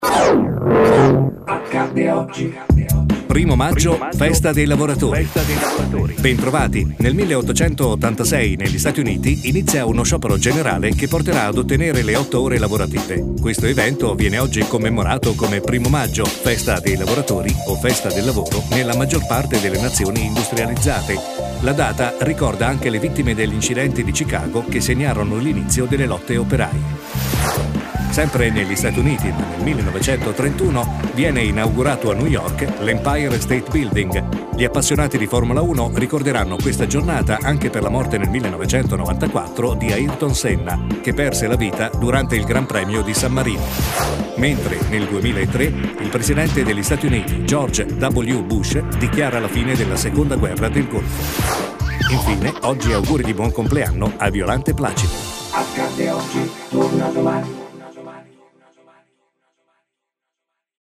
1 blocco da 1 minuto su bianco e con jingle iniziale e finale